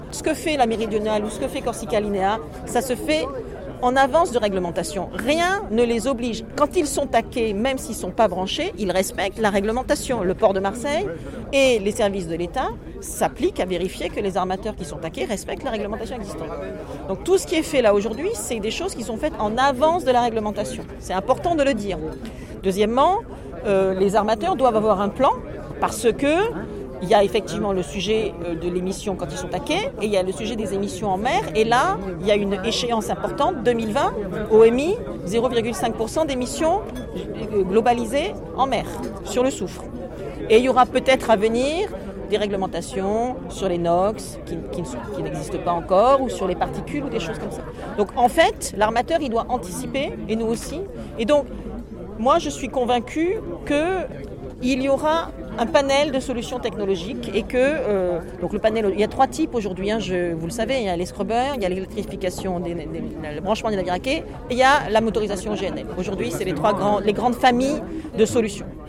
Ce branchement électrique à quai précise-t-il, est financé à 70% par Corsica Linea, 15% par l’Ademe et 15% par la Région Sud pour un montant total de 4,6M€ et sera opérationnel en 2019… Entretien